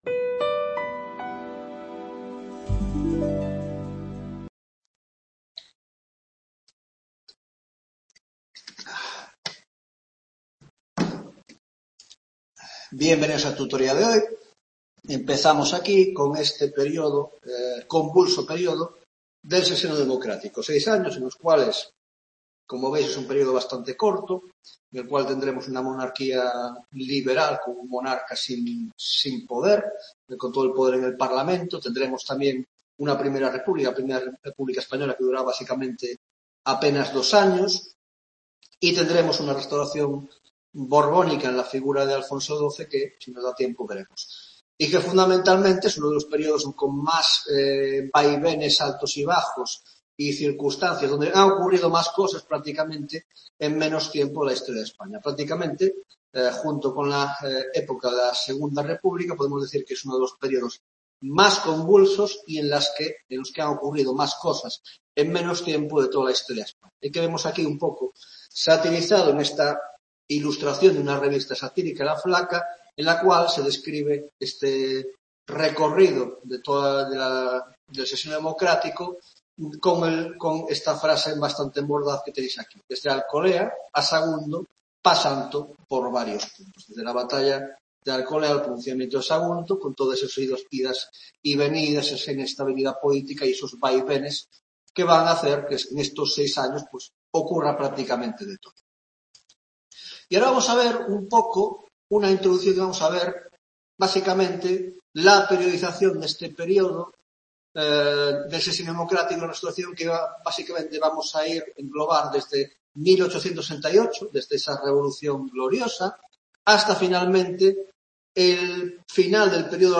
9ª tutoría de Historia Contemporánea